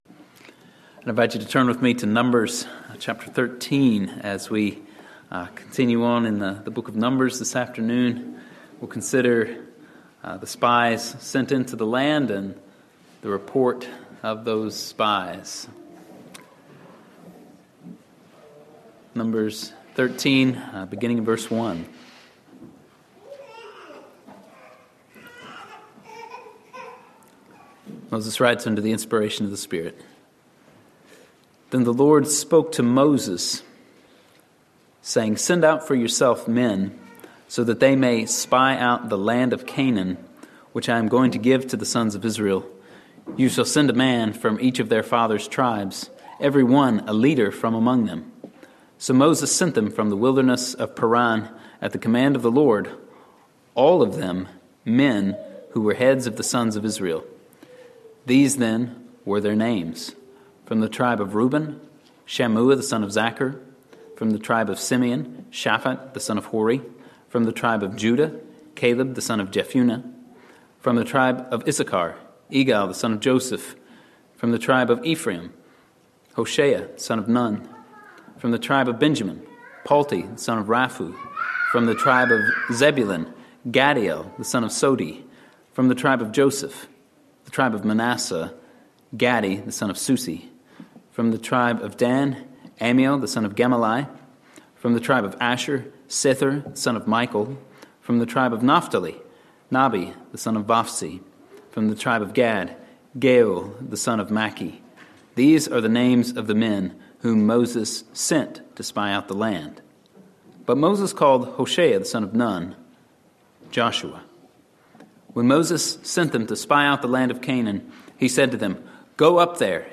Sermons from Andover Baptist Church in Linthicum, MD.